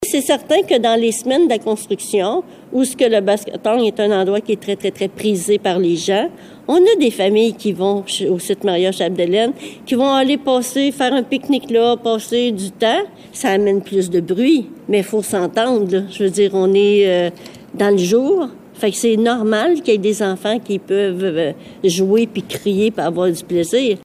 Tout d’abord, un règlement stipulant qu’aucun achalandage ne devrait être toléré au site Maria Chapdelaine a été annulé. C’est pour contrôler le bruit, entre autres, que ce règlement avait été créé, avant le mandat de la mairesse actuelle, Jocelyne Lyrette, qui explique :